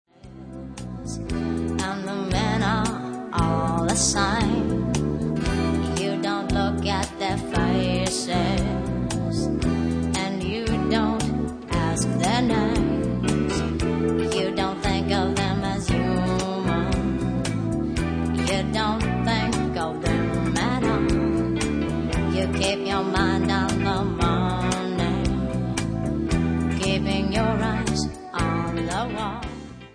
R&B KARAOKE MUSIC CDs
w/vocal